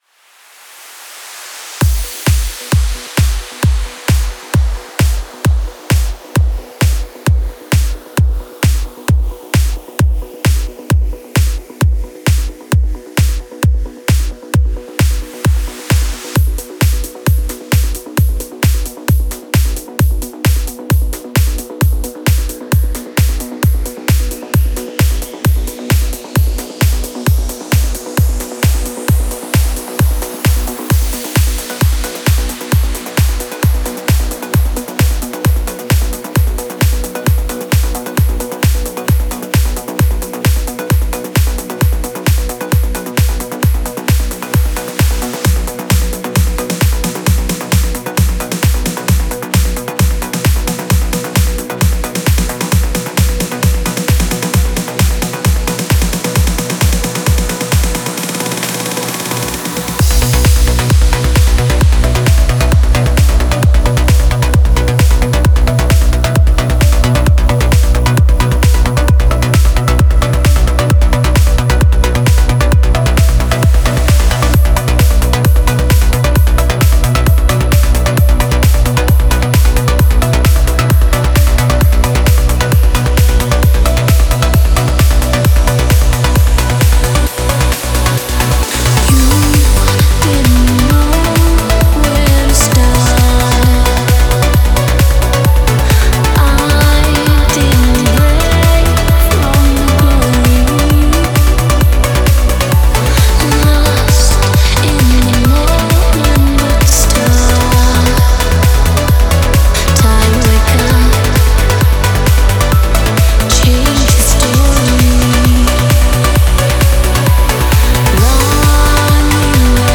Стиль: Progressive Trance